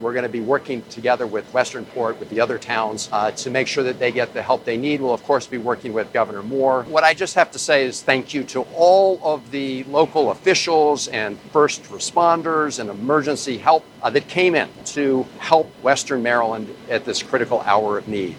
Van Hollen added his voice of support for recovery efforts offered by the state…